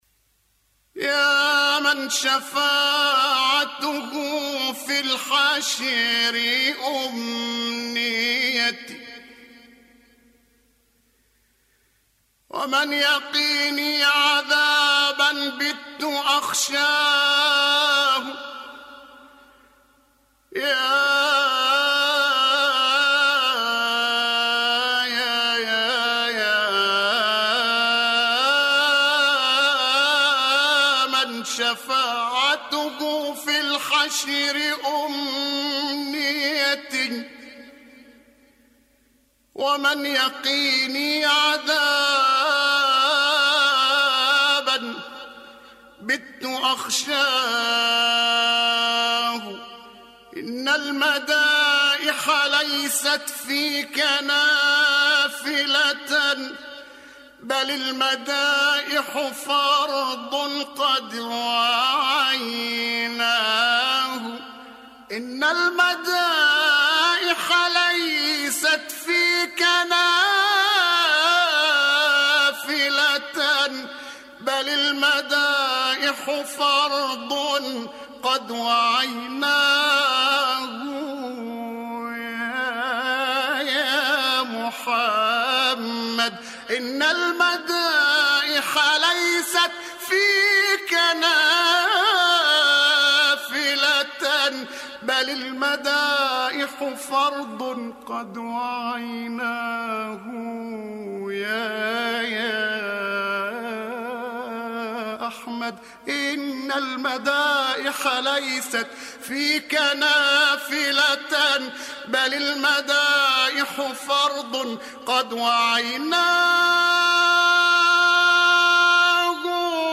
يا من شفاعته في الحشر أمنيتي.. ابتهال جميل للمبتهل الشهير الشيخ البساتيني
اسلاميات - الكوثر: ابتهال جميل في مدح النبي المصطفى (صلى الله عليه وآله) بصوت المبتهل المصري الراحل عبد التواب البساتيني.
مدائح نبوية